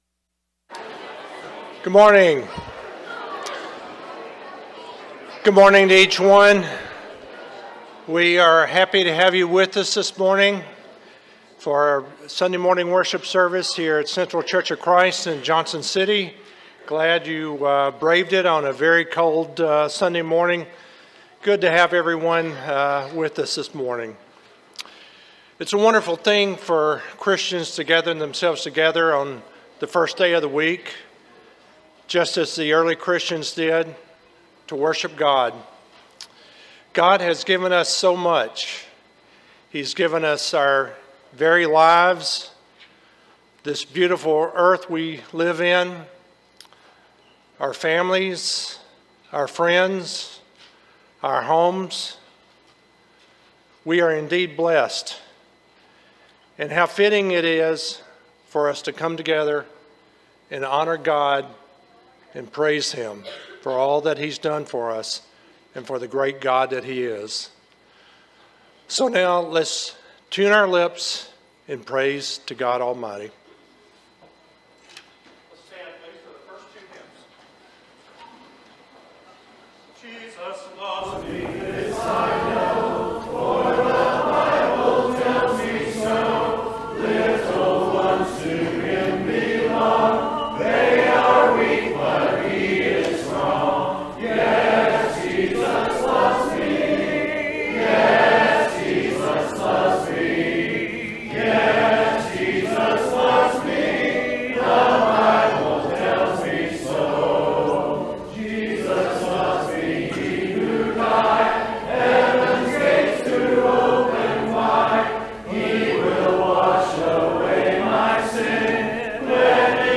Luke 2:10-11, English Standard Version Series: Sunday AM Service